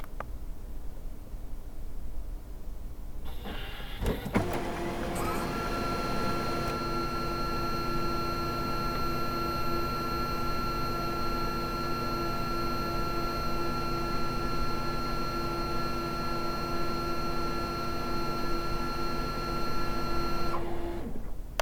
製品の稼動中に以下のファイルの音が発生しますが、正常な稼動音です。
・電源ON時
・節電復帰時
・プリンター調整中